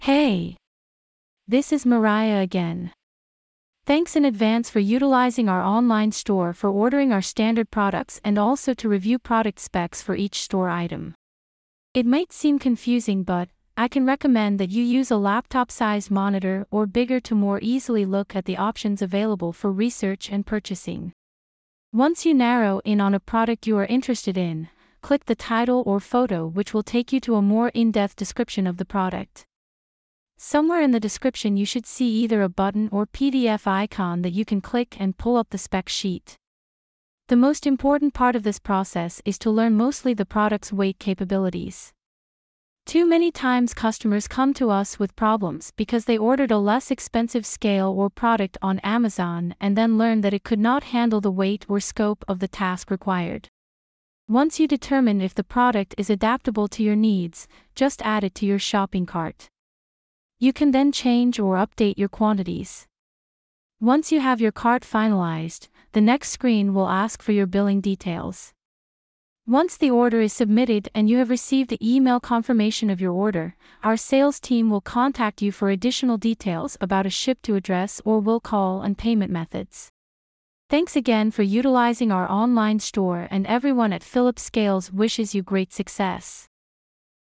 LISTEN TO THE AUDIO TUTORIAL TO HELP WITH THE ONLINE STORE PROCESS